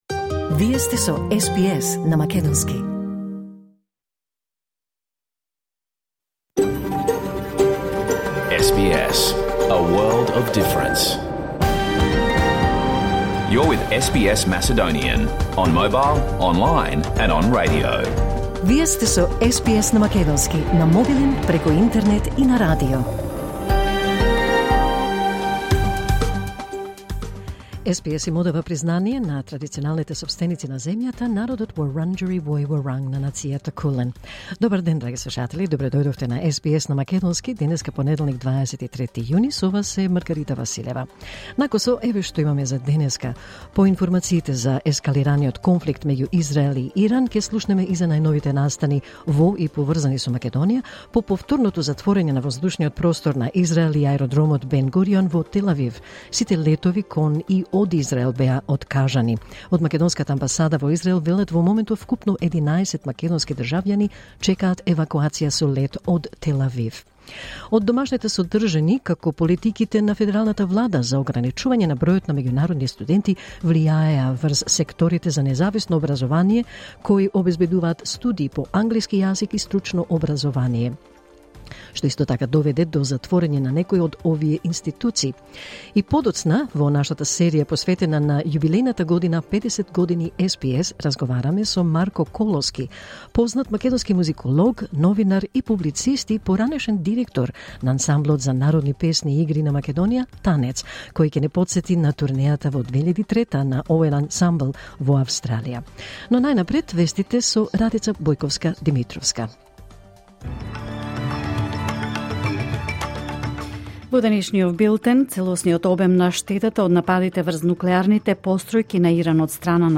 SBS Macedonian Program, broadcast live Monday to Friday with a repeat of Friday's program each Saturday, also at noon.